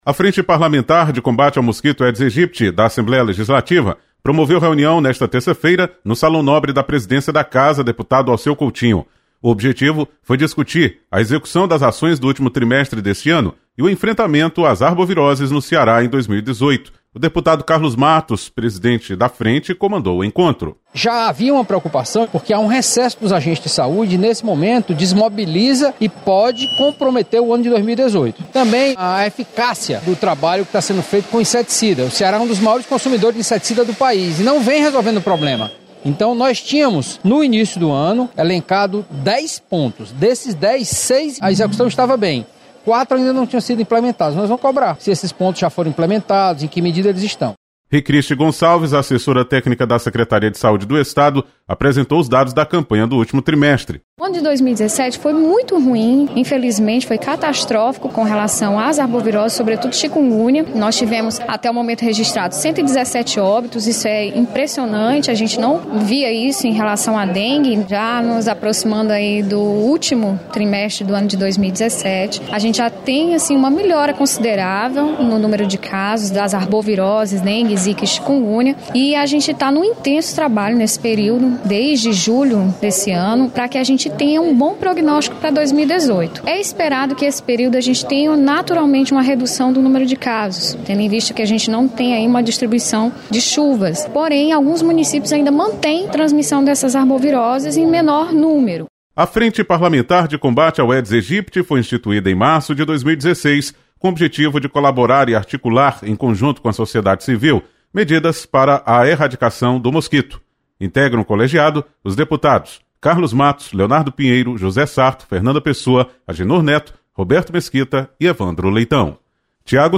Frente Parlamentar debate estratégias de combate ao Aedes Aegypti. Repórter